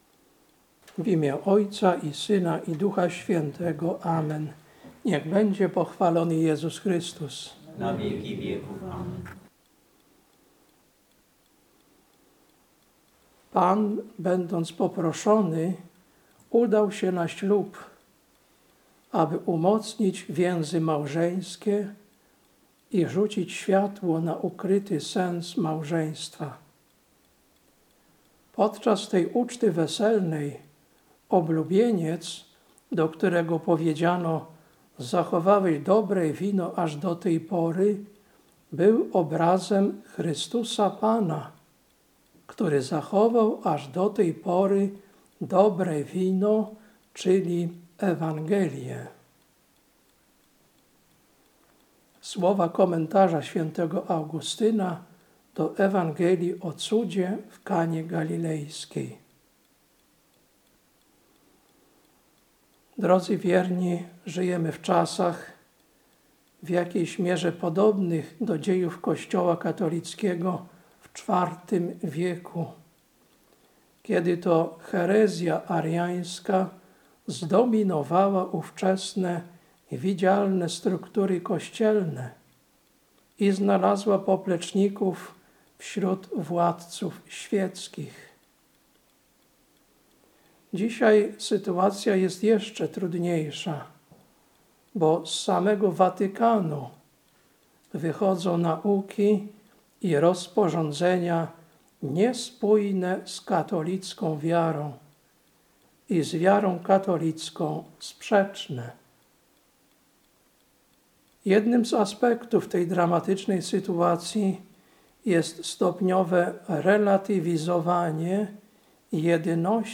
Kazanie na II Niedzielę po Objawieniu, 19.01.2025 Lekcja: Rz 12, 6-16 Ewangelia: J 2, 1-11